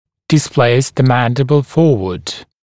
[dɪs’pleɪs ðə ‘mændɪbl ‘fɔːwəd(z)][дис’плэйс зэ ‘мэндибл ‘фо:уэд(з)]смещать нижнюю челюсть вперед